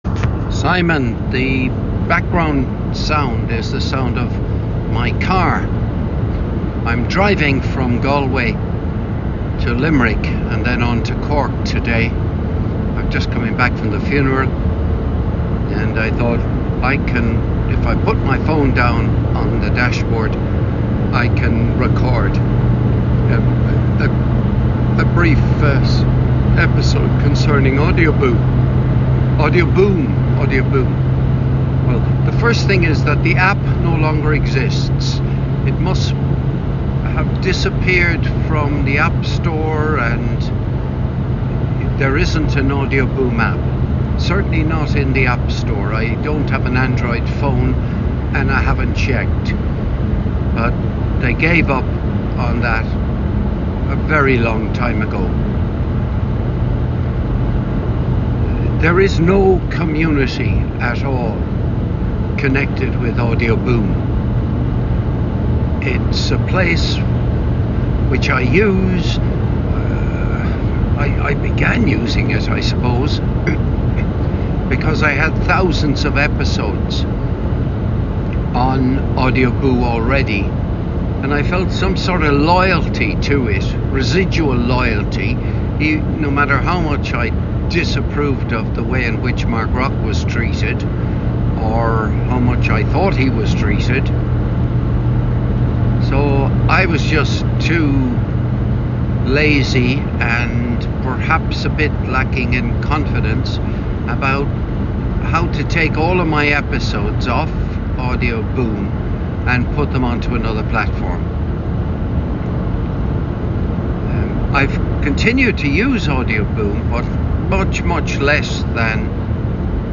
This episode was recorded on 20th November 2024 - in a battered old Audi A3